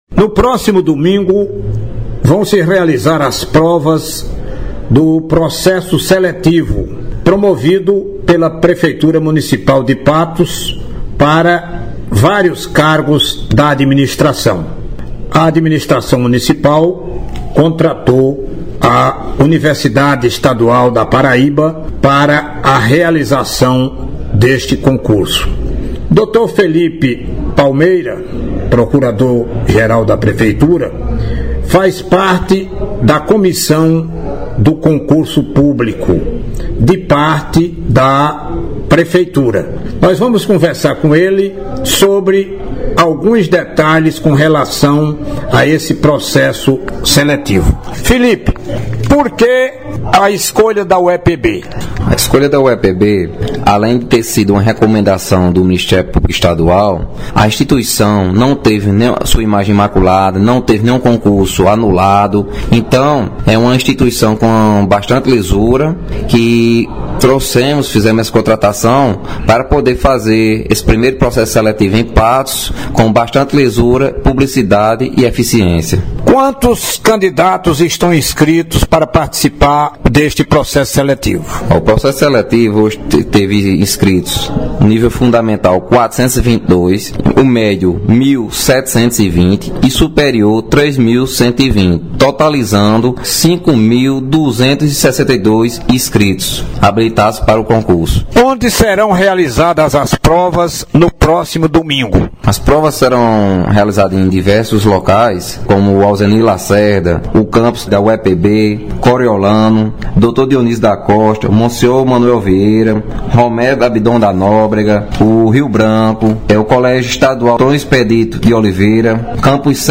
• Entrevista com o procurador geral do Município de Patos – Phillipe Palmeira